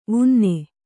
♪ vunne